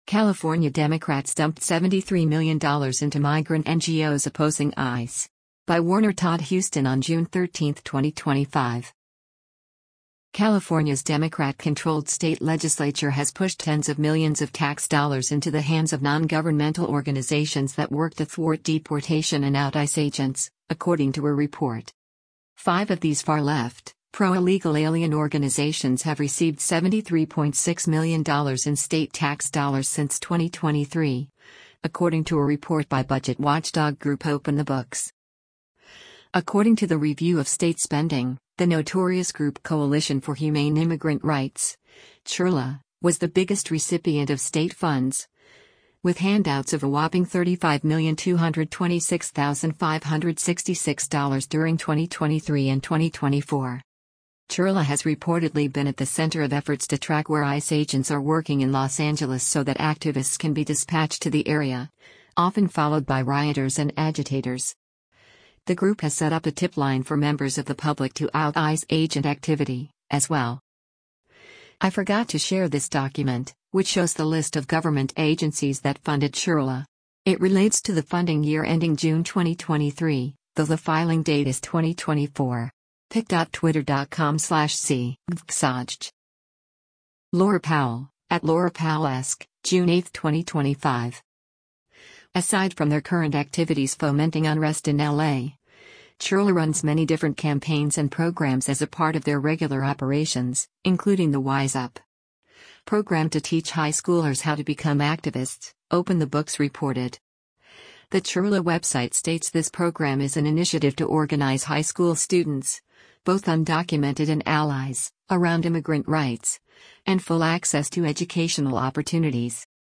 EDS NOTE: OBSCENITY A flash bomb explodes on the 101 Freeway near the metropolitan detention center of downtown Los Angeles, Sunday, June 8, 2025.